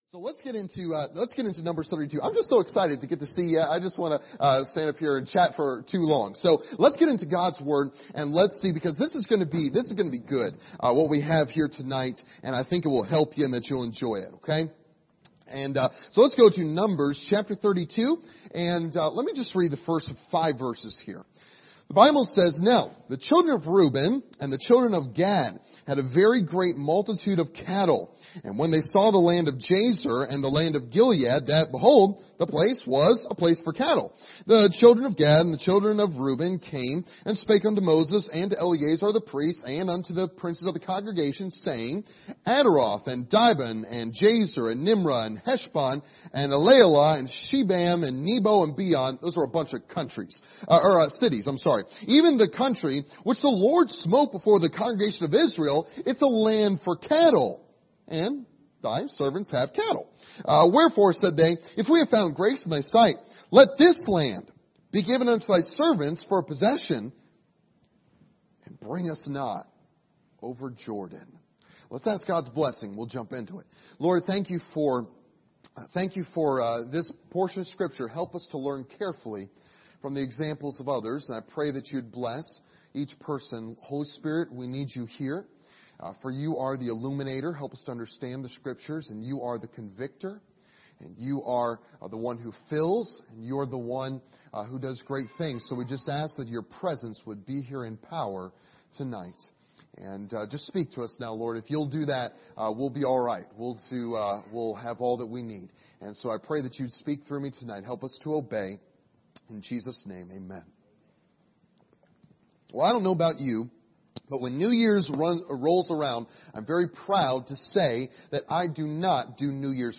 Passage: Numbers 32 Service Type: Revival Service